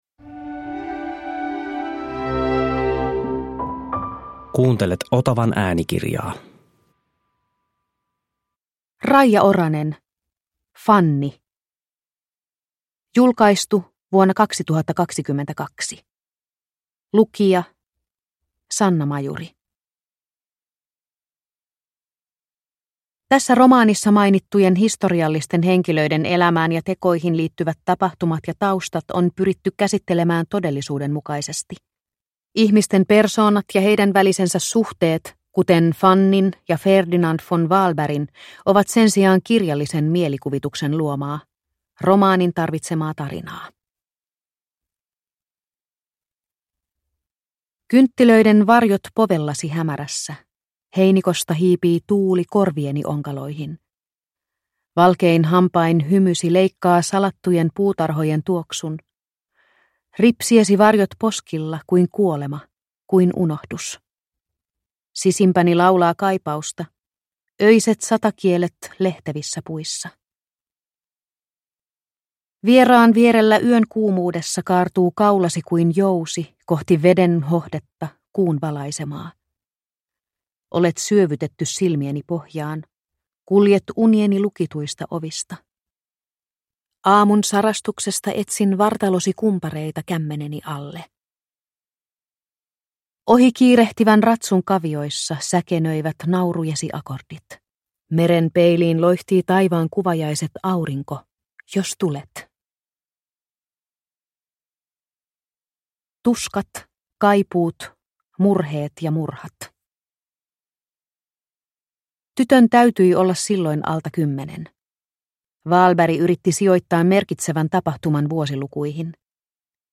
Fanny – Ljudbok – Laddas ner